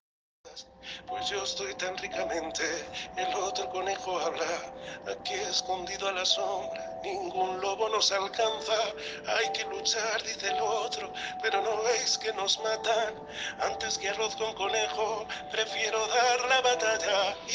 La canción inicia con el sonido de una guitarra acústica.